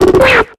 Audio / SE / Cries / TRAPINCH.ogg